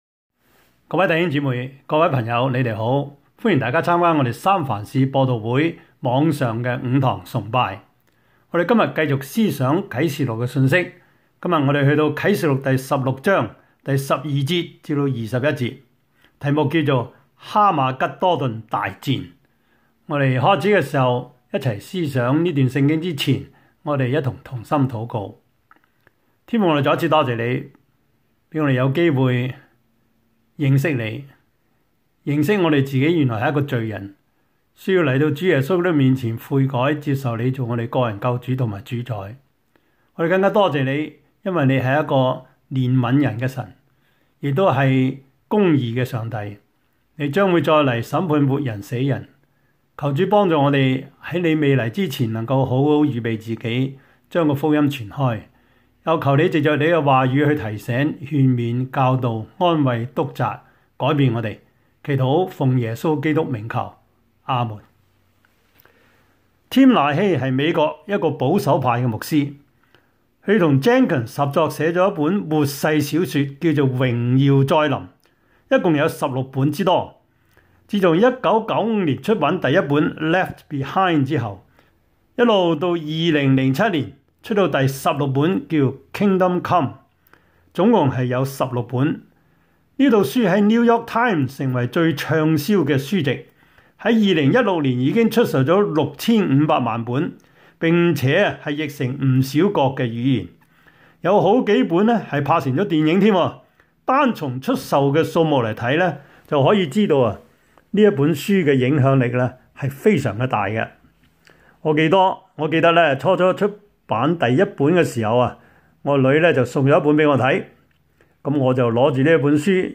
Service Type: 主日崇拜
Topics: 主日證道 « 你們說我是誰?